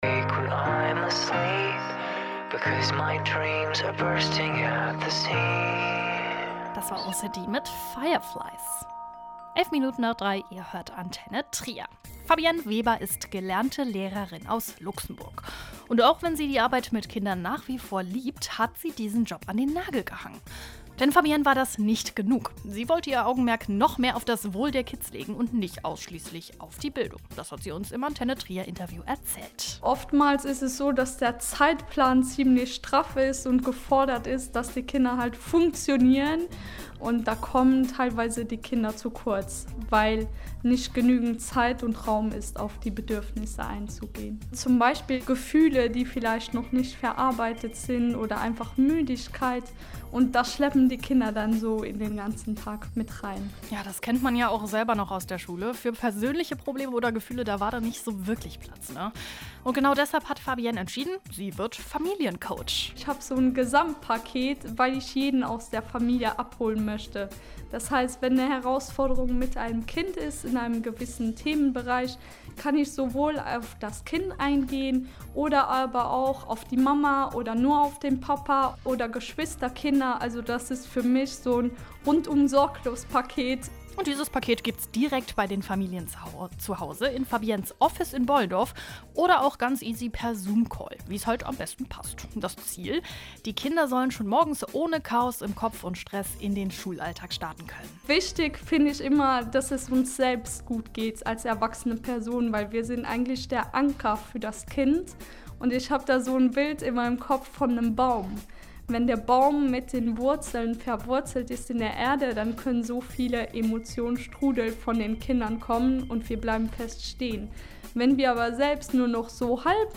Kurze Zeit später ging es für mich nach Trier zu einem Interview.